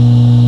transformer.wav